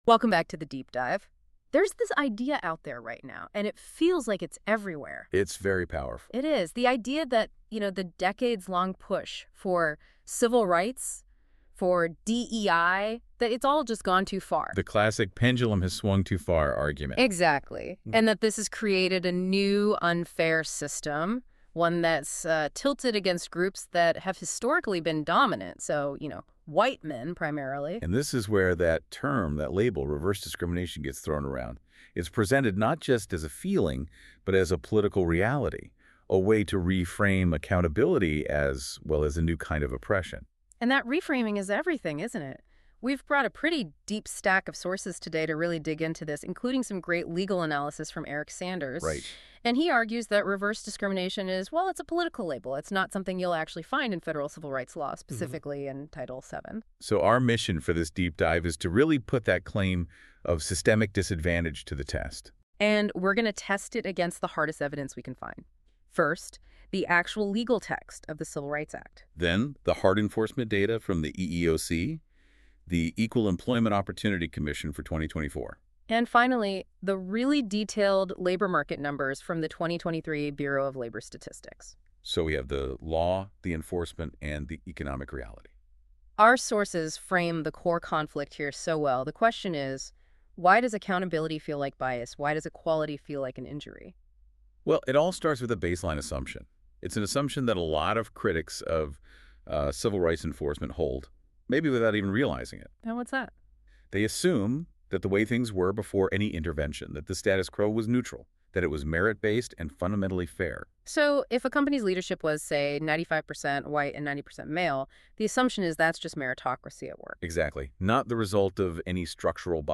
Second, a Deep-Dive Podcast that expands on the analysis in conversational form. The podcast explores the historical context, legal doctrine, and real-world consequences in greater depth, including areas that benefit from narrative explanation rather than footnotes.